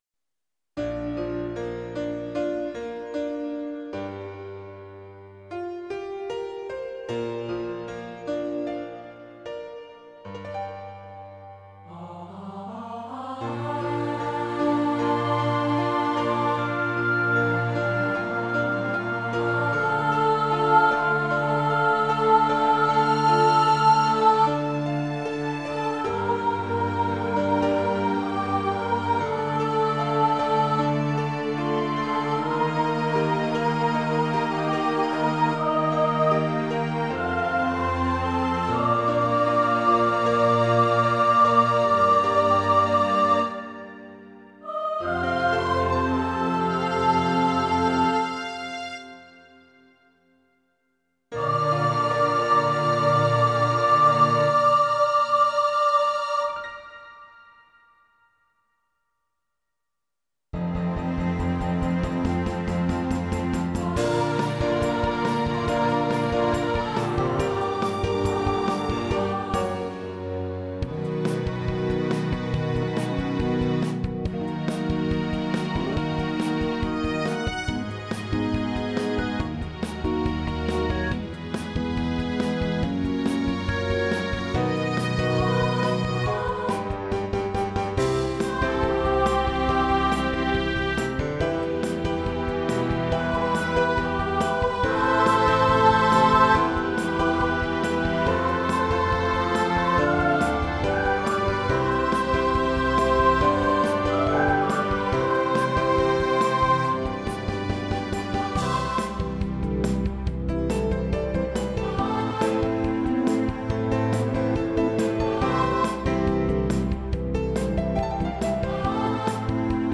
I-Will-Follow-Him-Soprano-V3 | Ipswich Hospital Community Choir
I-Will-Follow-Him-Soprano-V3.mp3